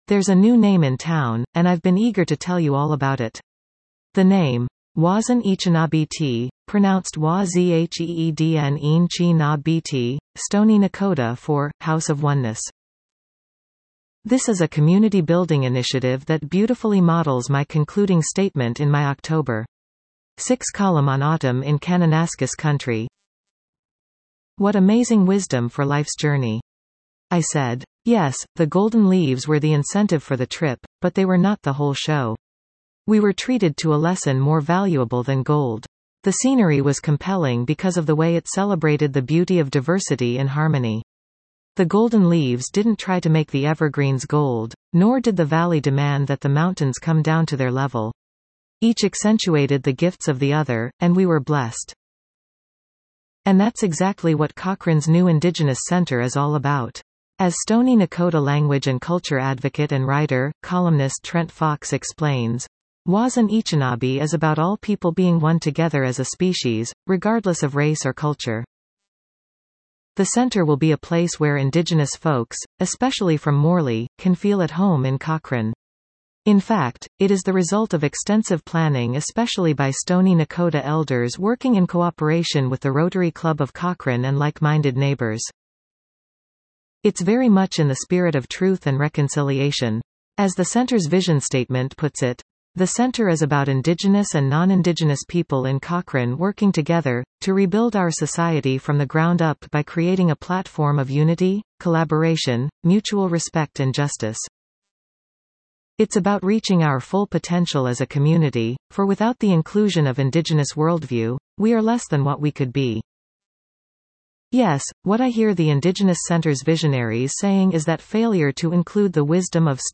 The name: Wazin Îchinabi Ti (pronounced wah-ZHEEDN een-chee-NAH-bee TEE), Stoney Nakoda for “House of Oneness.”